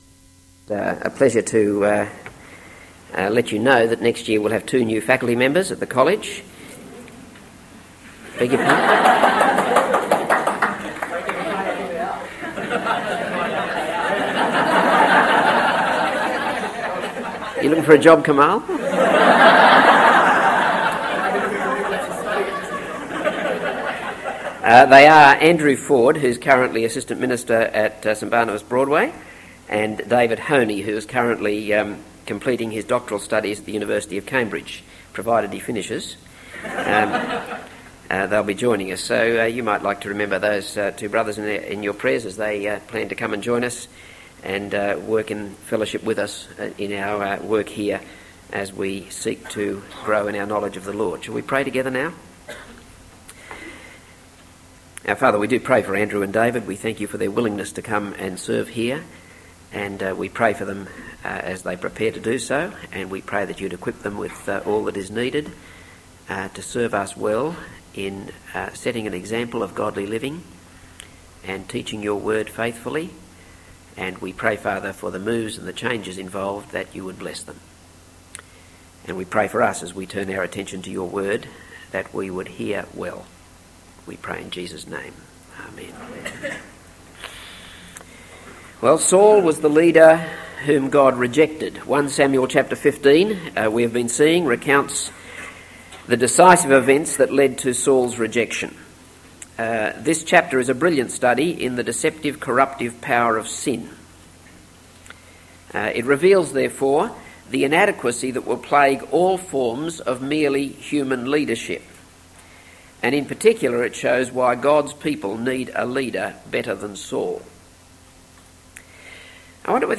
This is a sermon on 1 Samuel 15.